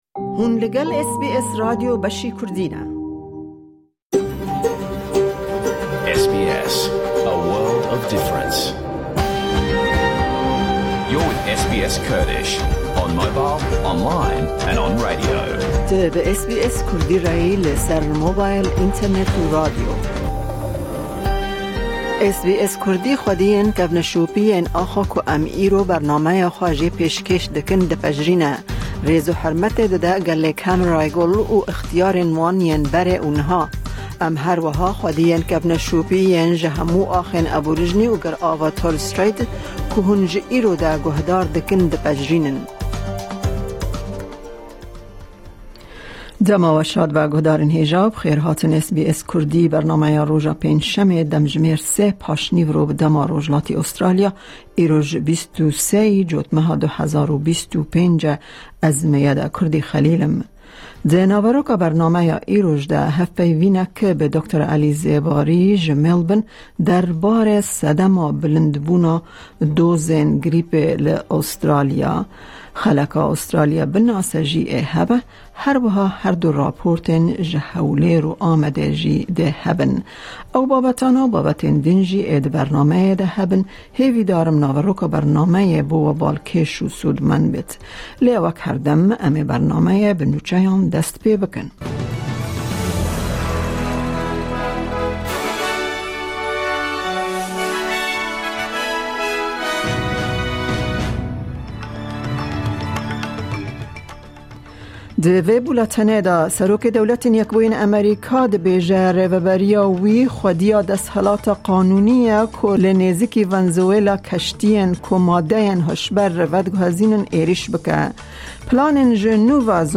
Nûçe, herdu raportên ji Hewlêr û Amedê têde hene.